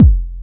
Kick Syn 01 X5.wav